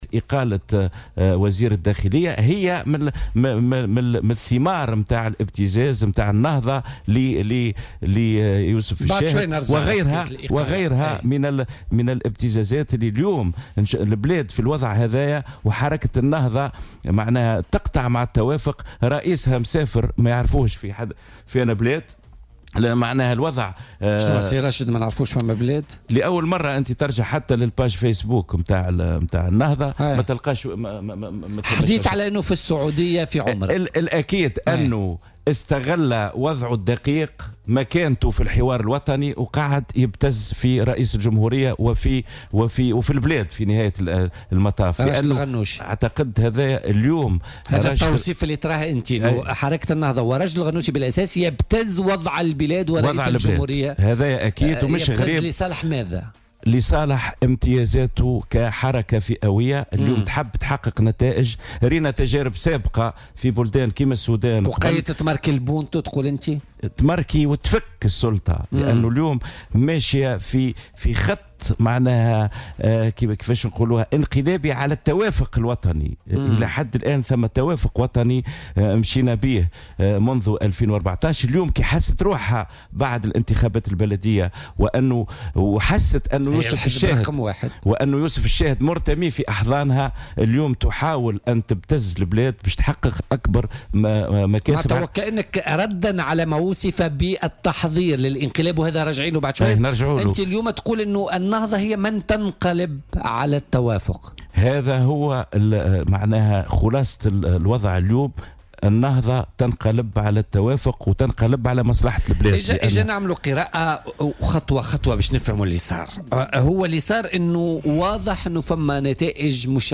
وأضاف ضيف "بوليتيكا" على "الجوهرة أف أم" أن النهضة ضد تواجد براهم على رأس الداخلية وقد استغلّ الشاهد هذا العامل لاتخاذ قرار الإعفاء بعد أن عمل على تعطيله على مستوى التعيينات بوزارة الداخلية.